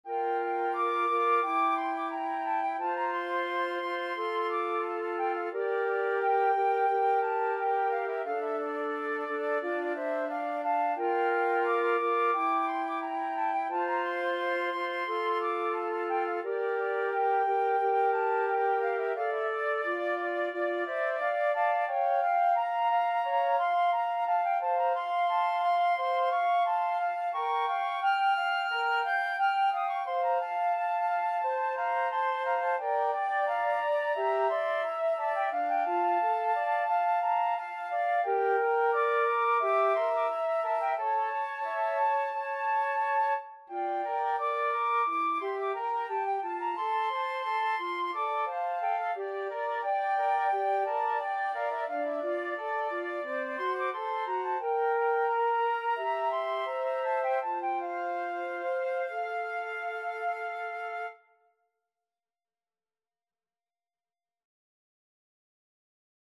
Free Sheet music for Flute Trio
F major (Sounding Pitch) (View more F major Music for Flute Trio )
4/4 (View more 4/4 Music)
Molto allegro =176
Classical (View more Classical Flute Trio Music)